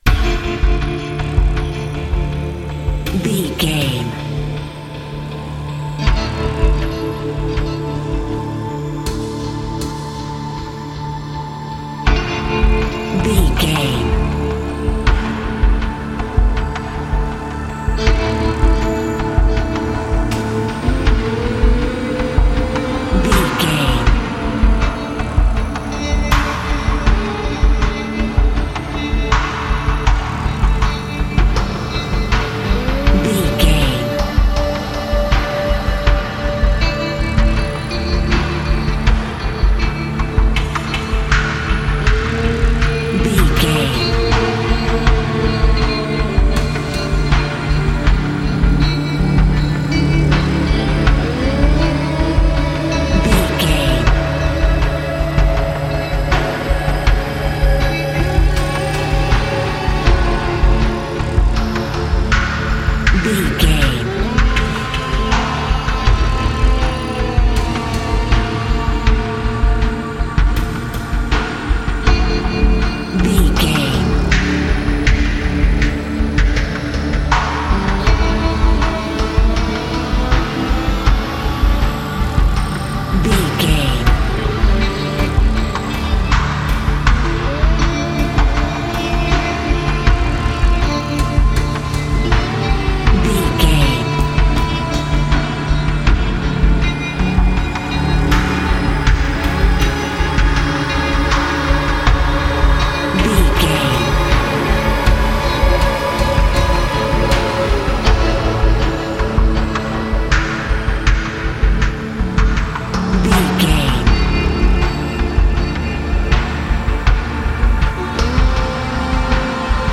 Thriller
Aeolian/Minor
Slow
drum machine
synthesiser
electric piano
electric guitar